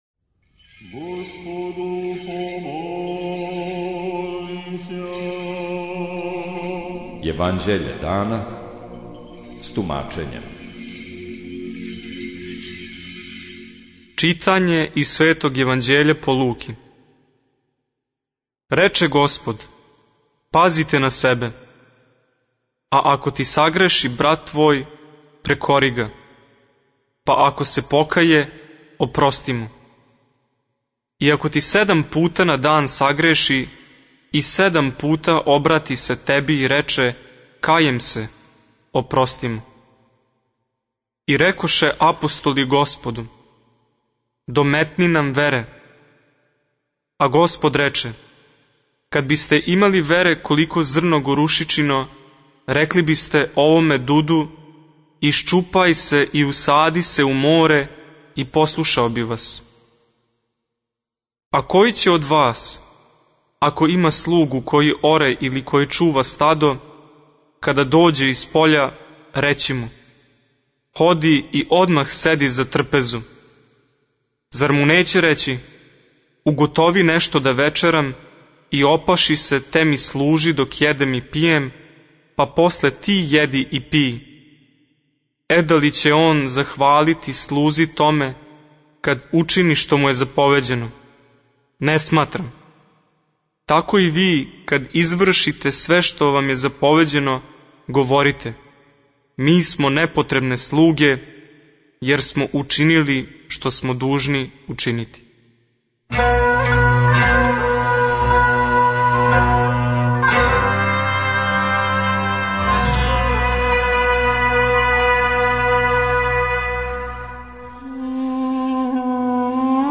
Читање Светог Јеванђеља по Матеју за дан 24.06.2023. Зачало 24.